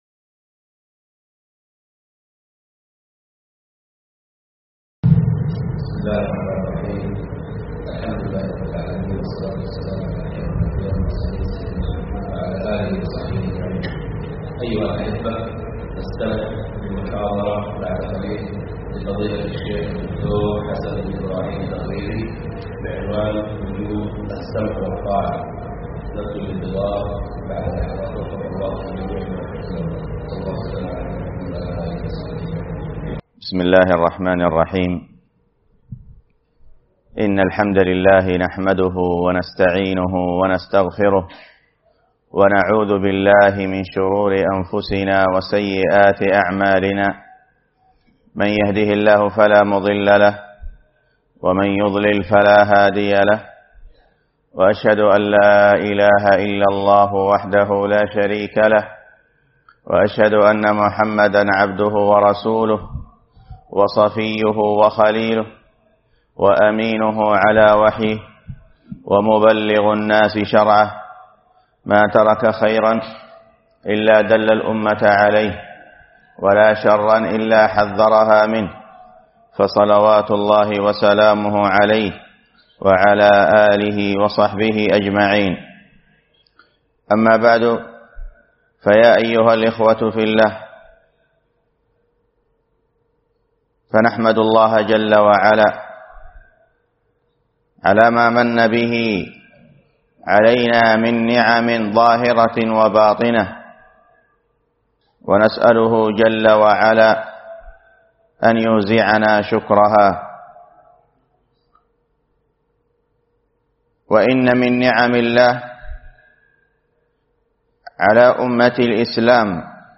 محاضرة
جامع الشيخ حافظ الحكمي بمحافظة صامطة